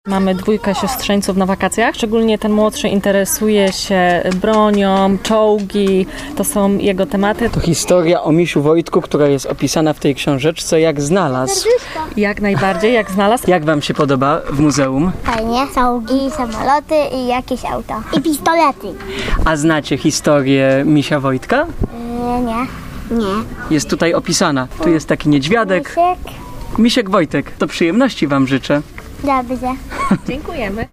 - mówi uczestniczka pikniku.